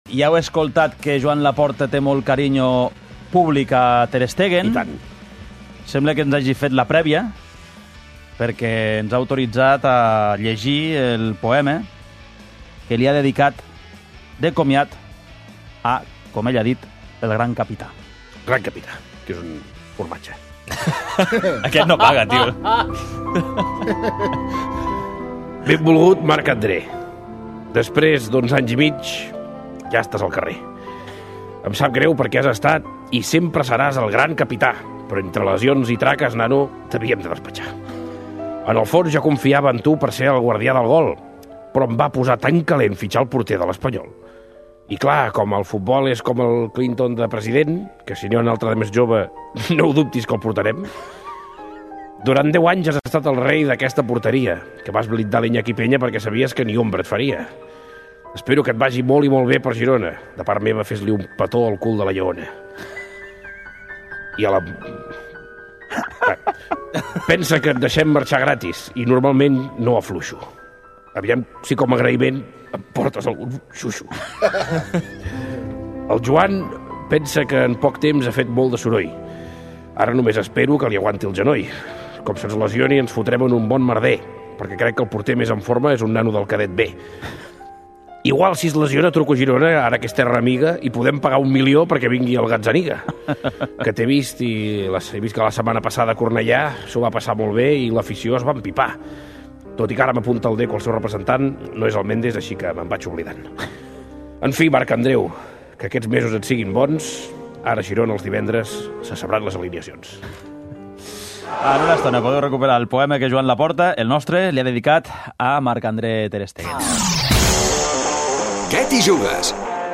Al 'Què t'hi jugues!', el nostre president del FC Barcelona dedica uns versos al gran capità i ja nou porter del Girona